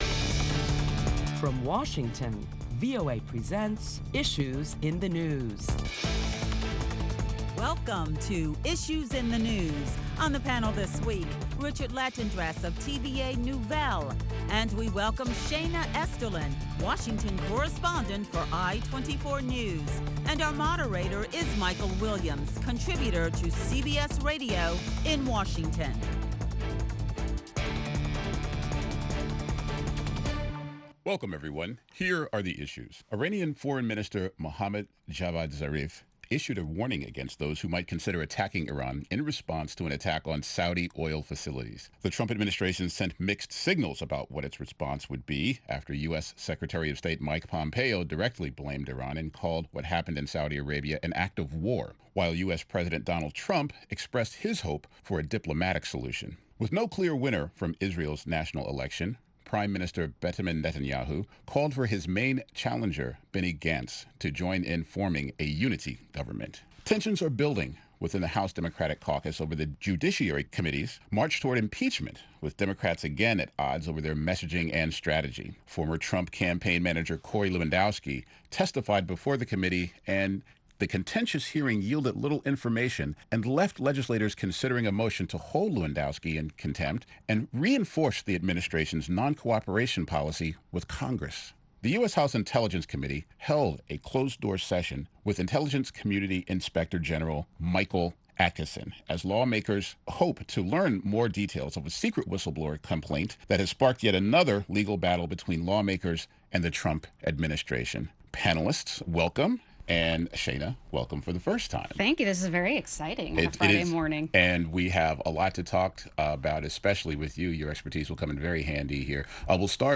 Listen to a panel of prominent Washington journalists as they deliberate the latest top stories that include tension builds within the House Democratic Caucus over a march towards impeaching President Trump. And, will a unity government be formed in Israel.